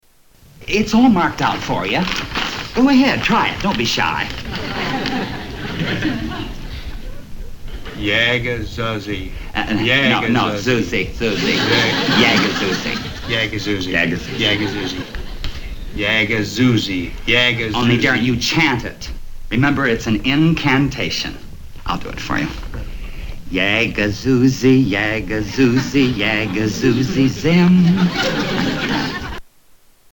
A chant